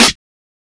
Snare
Original creative-commons licensed sounds for DJ's and music producers, recorded with high quality studio microphones.
2000s Clean Steel Snare Drum Sound G# Key 97.wav
00s-subtle-reverb-snare-drum-g-sharp-key-06-VZc.wav